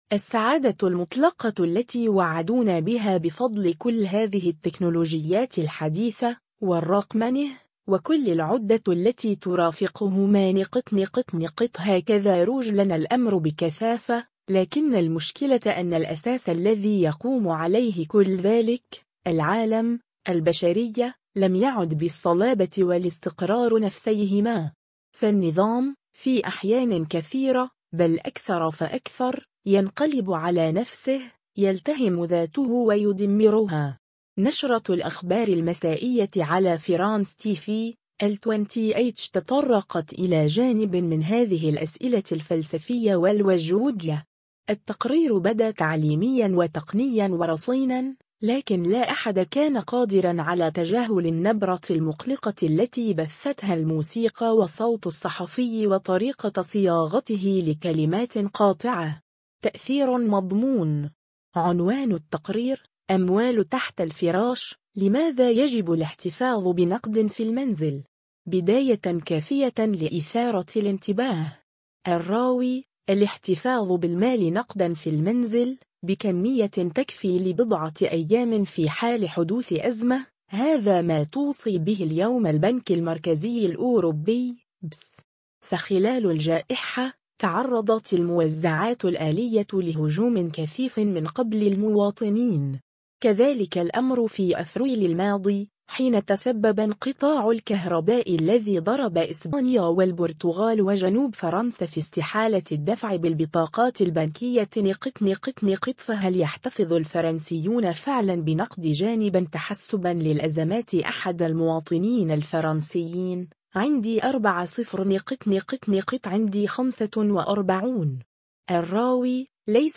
نشرة الأخبار المسائية على «فرانس تي في» (الـ20H) تطرّقت إلى جانب من هذه الأسئلة الفلسفية و الوجودية.
التقرير بدا تعليميًا و تقنيًا و رصينًا، لكن لا أحد كان قادرًا على تجاهل النبرة المقلقة التي بثّتها الموسيقى و صوت الصحفي و طريقة صياغته لكلمات قاطعة.